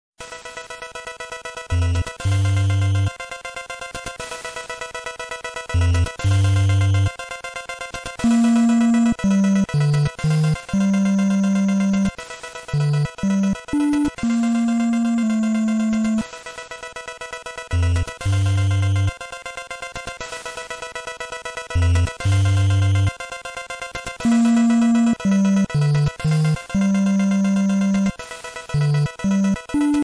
contribs)applied fade-out and vorbis